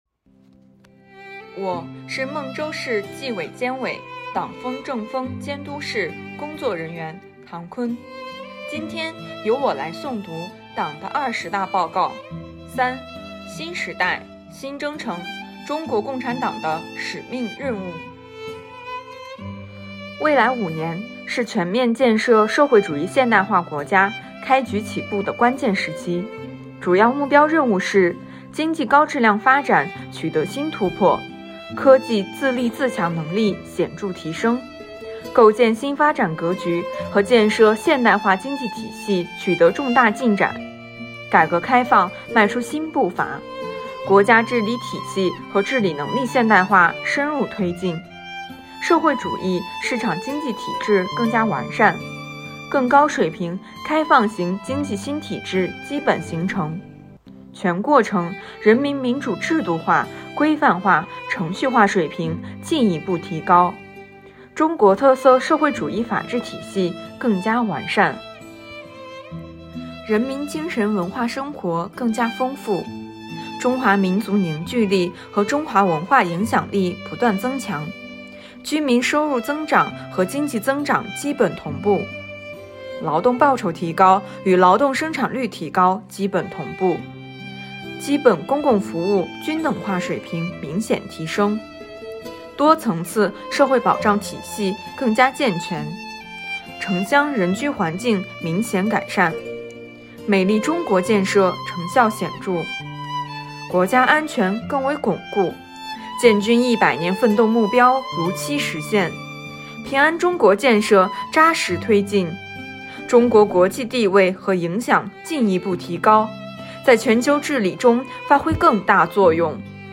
诵读二十大报告第三部分（下）.mp3